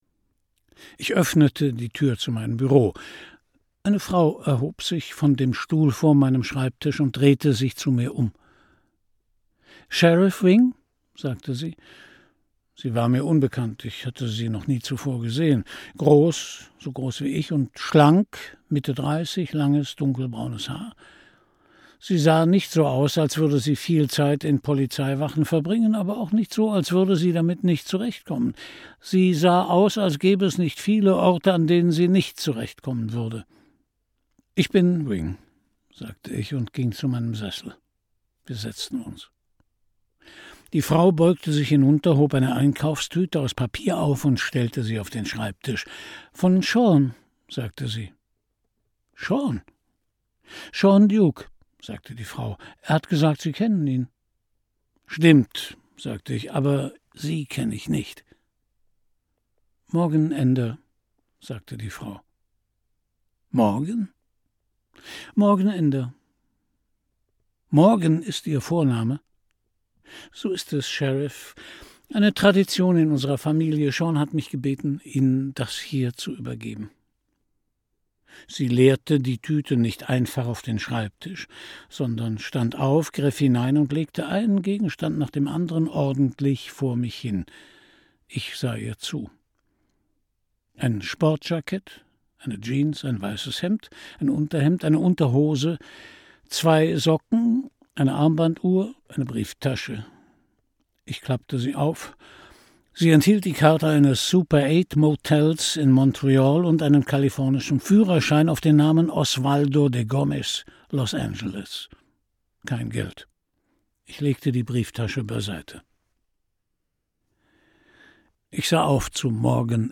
Christian Brückner (Sprecher)
Schlagworte Anthony Hopkins • Belletristik in Übersetzung • Cozy Mystery / Cosy Mystery / Provinzkrimi • Hörbuch; Krimis/Thriller-Lesung • Krimi • Moderne und zeitgenössische Belletristik • Parlando Verlag • Ray Liotta • Sheriff • USA • USA; Krimis/Thriller • Vereinigte Staaten von Amerika, USA • Vermont • Western